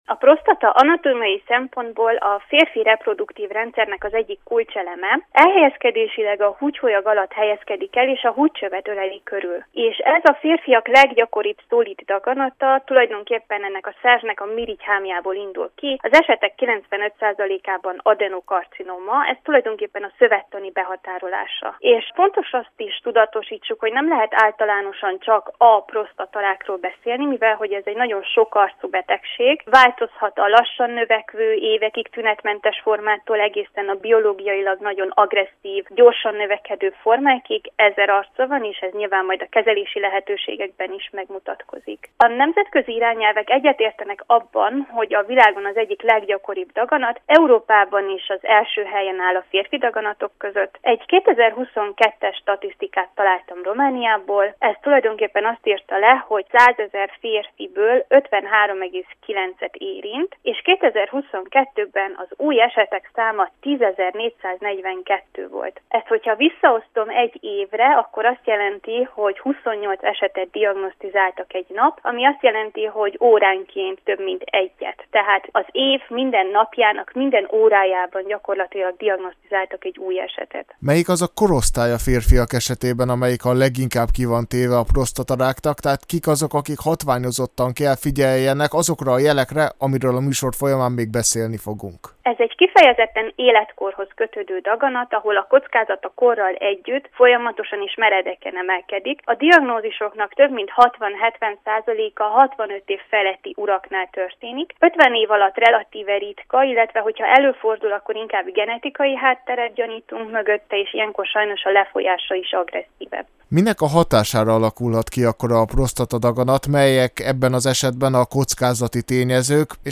beszélgetett Medicina című műsorunkban